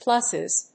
発音記号
• / ˈplʌsɪz(米国英語)